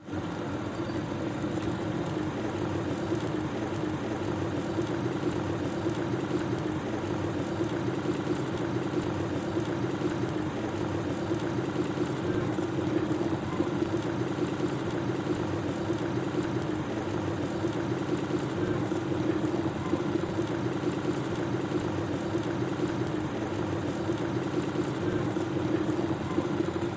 Tiếng Ấm nước Sôi sùng sục
Thể loại: Tiếng động
Description: Hiệu ứng âm thanh tiếng Ấm nước Sôi sùng sục, tiếng nấu nước bình nước bình siêu tốc sôi, tiếng đun nước ấm siêu tốc sôi, sủi bọt, water boiling in a kettle, kettle sound & boiling water sound effect...
tieng-am-nuoc-soi-sung-suc-www_tiengdong_com.mp3